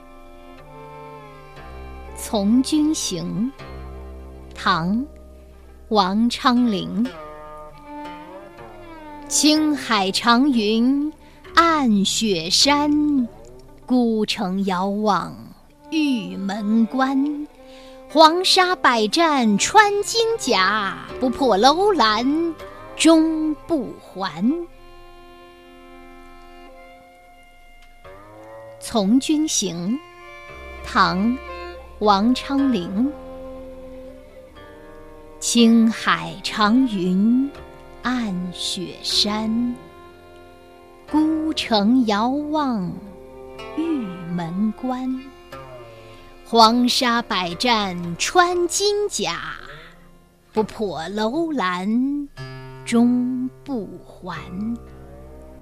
朗读：从军行.mp3